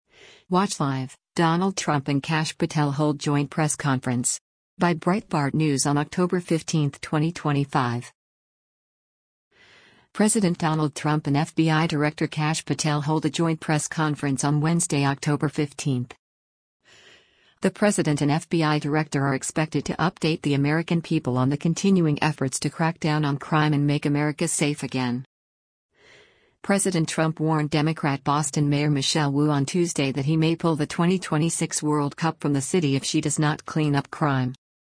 President Donald Trump and FBI Director Kash Patel hold a joint press conference on Wednesday, October 15.